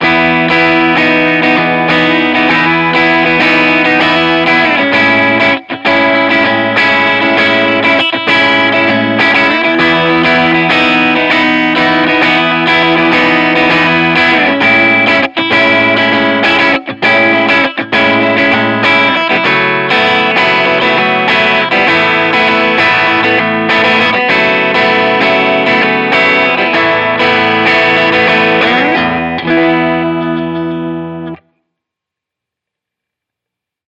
Электрогитара FENDER SQUIER AFFINITY TELECASTER MN BLACK